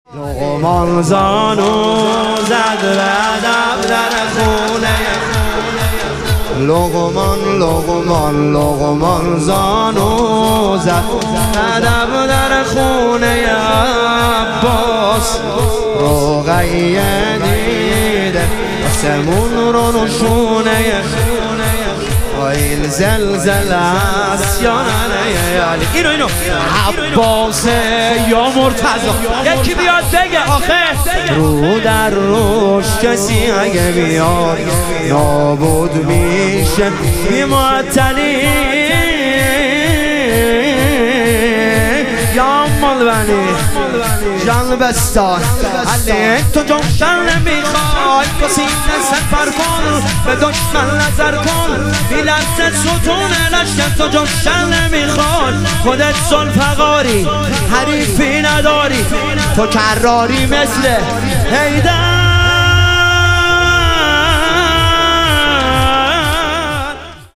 شور
شب شهادت امام هادی علیه السلام